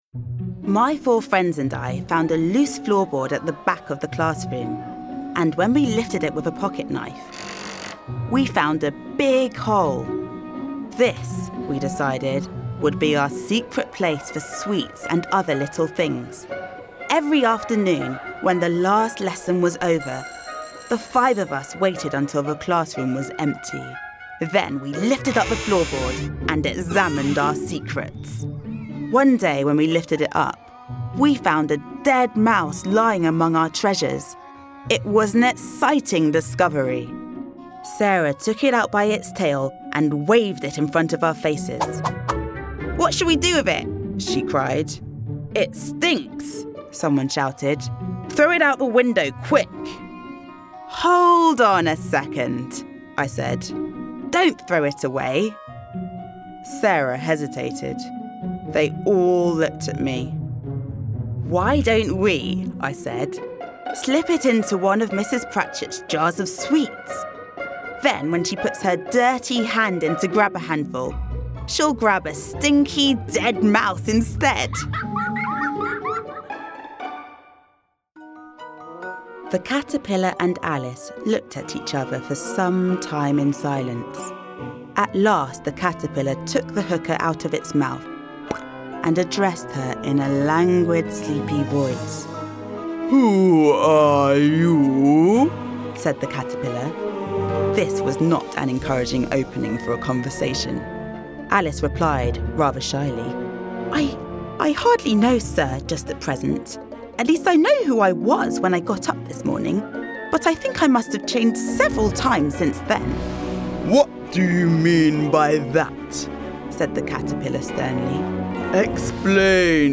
• Native Accent: London, RP
• Home Studio